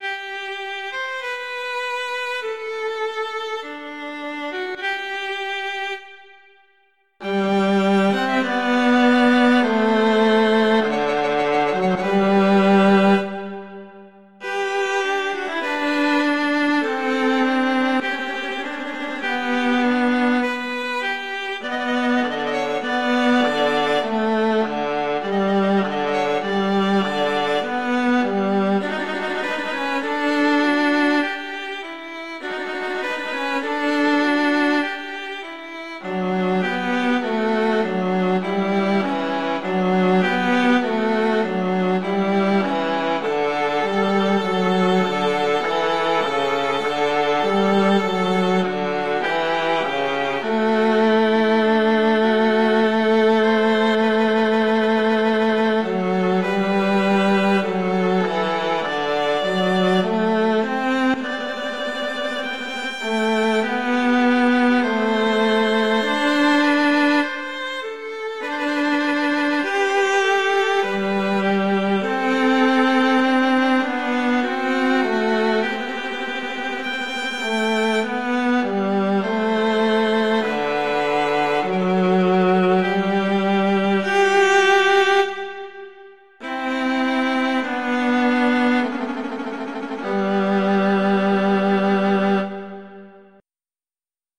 classical
Largo